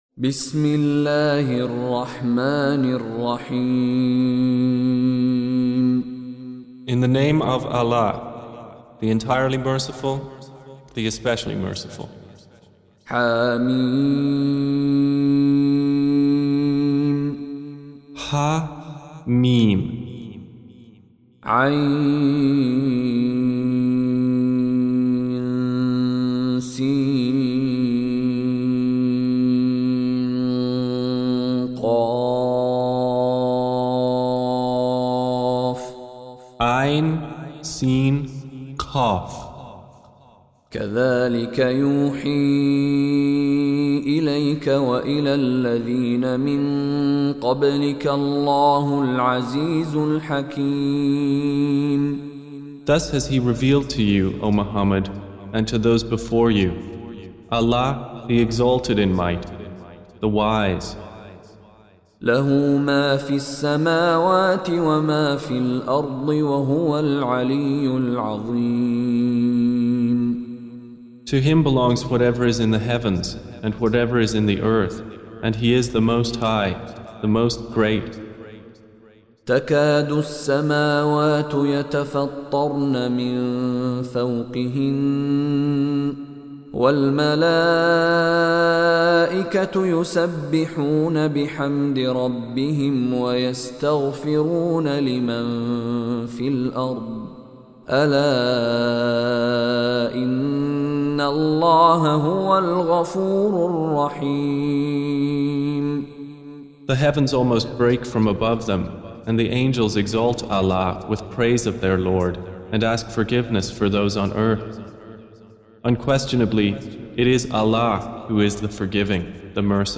Surah Repeating تكرار السورة Download Surah حمّل السورة Reciting Mutarjamah Translation Audio for 42. Surah Ash-Sh�ra سورة الشورى N.B *Surah Includes Al-Basmalah Reciters Sequents تتابع التلاوات Reciters Repeats تكرار التلاوات